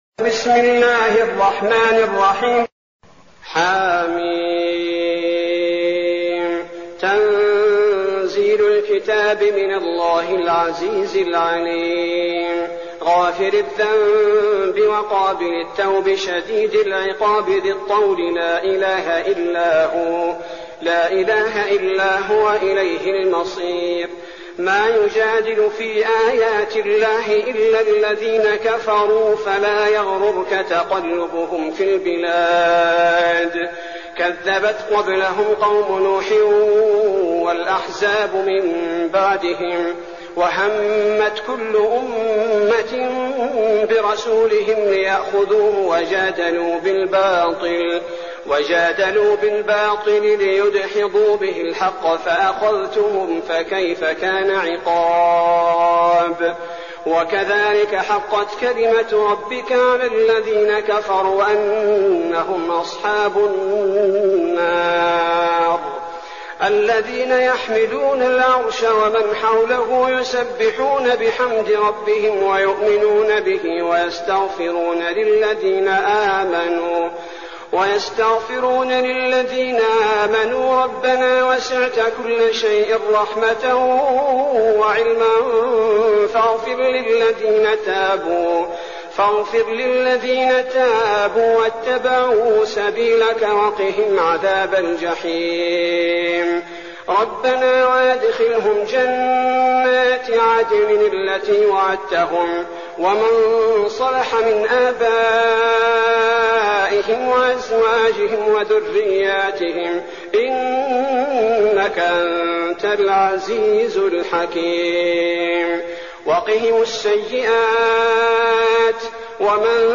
المكان: المسجد النبوي الشيخ: فضيلة الشيخ عبدالباري الثبيتي فضيلة الشيخ عبدالباري الثبيتي غافر